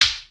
bonk.wav